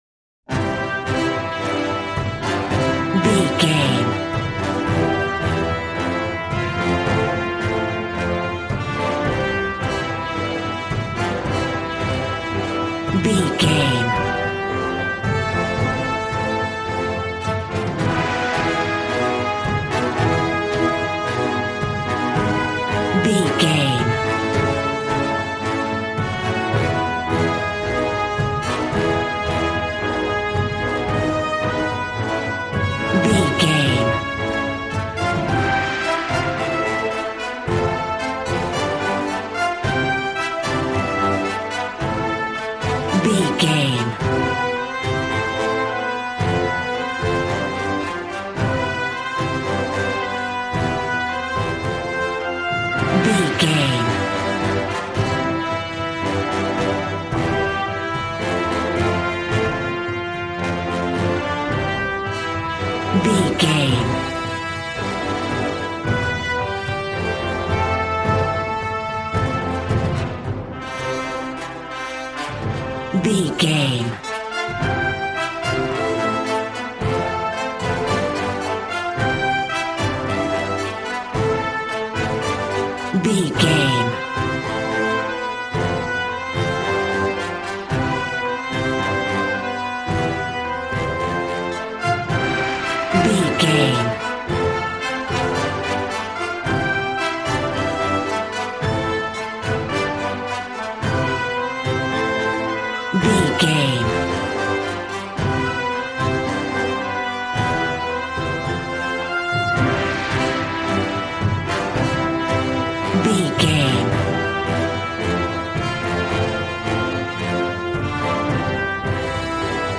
Aeolian/Minor
regal
cello
double bass